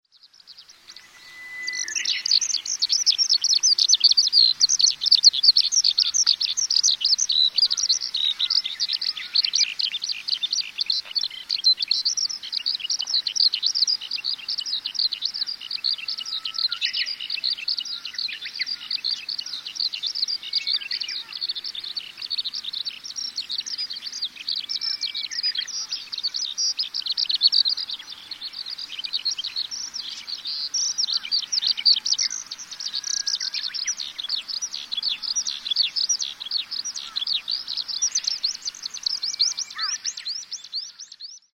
hibari_s1.mp3